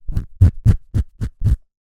Звуки человека
Человек царапает кожу крупным планом